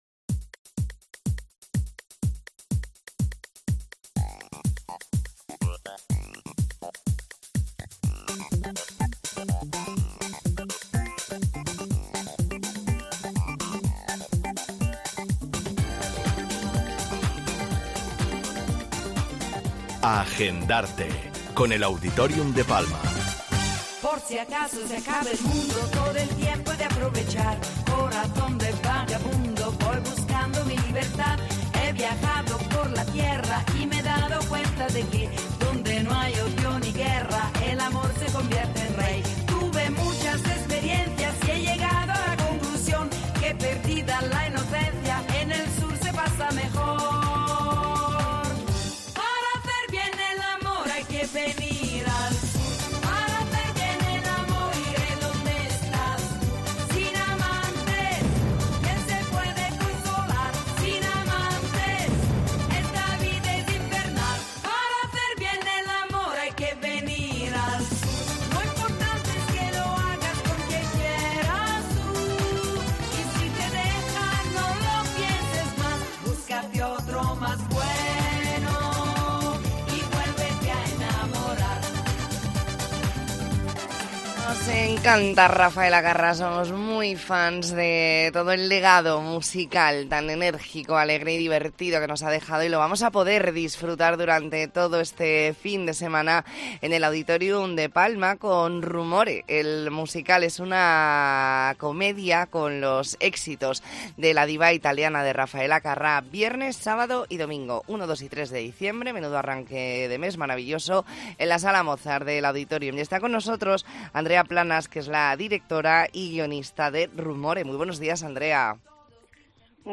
Entrevista en La Mañana en COPE Más Mallorca, jueves 30 de noviembre de 2023.